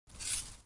Cusco Peru documentary » Footsteps across dry vegetation and solid dirt
描述：With mountain boots and a backpack. Sennheiser 416 p48 into a Zoom F8.
标签： ground vegetation footsteps foot peru steps cusco feet walk solid dirt walking dry
声道立体声